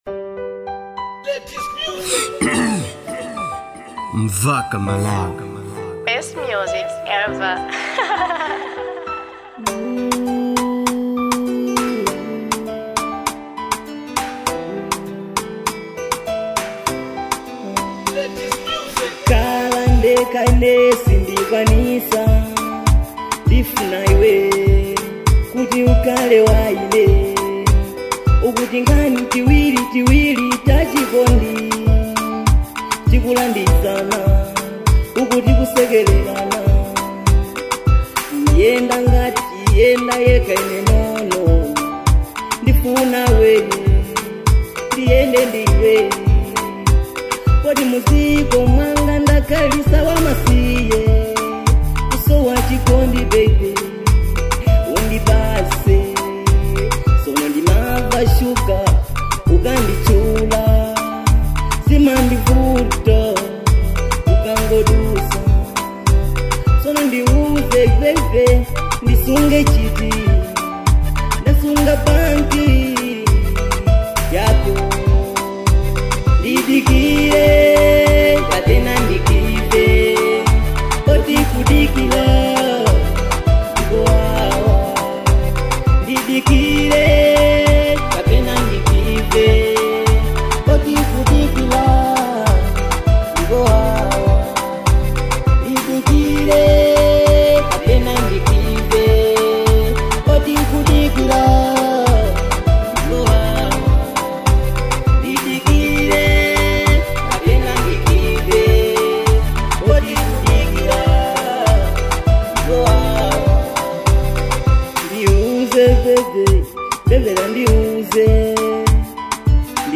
type: love song